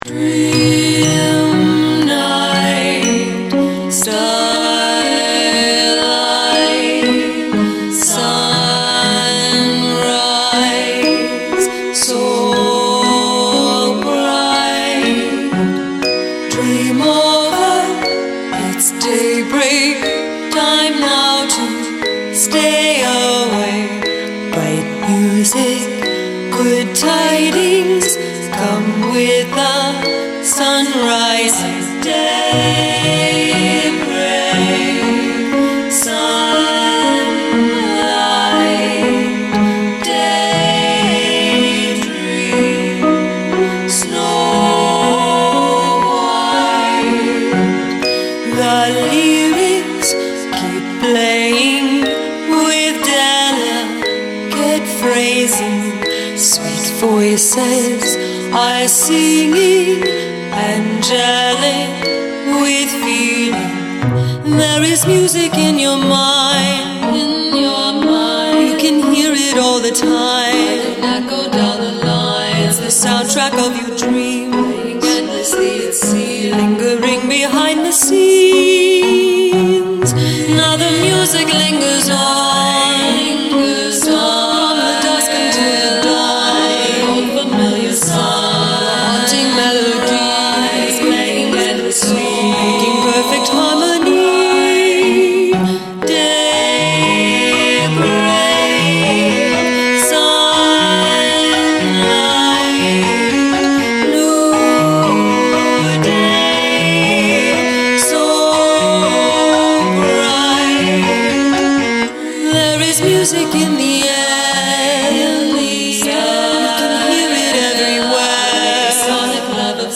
madrigal style polyphonic composition